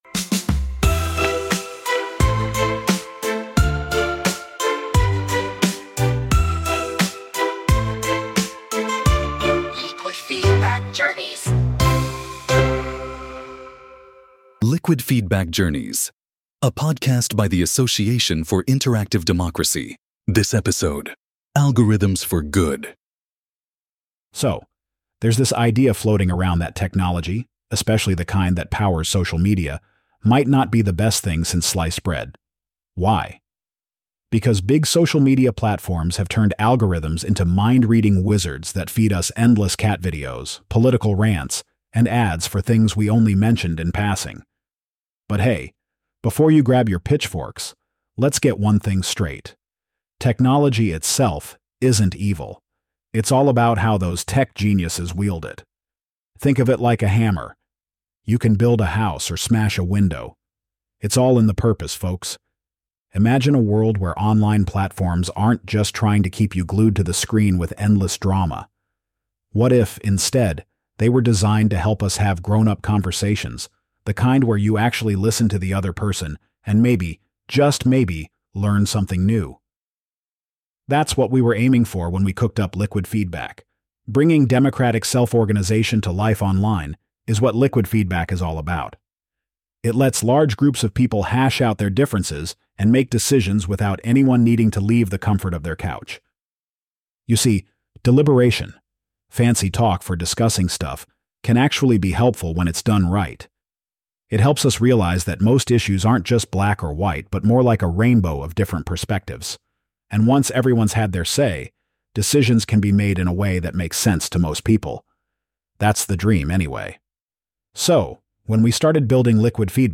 The accompanying song is "Digital Dawn."
Through a unique blend of stories, insights, and a mix of words and music, we bring these ideas to life.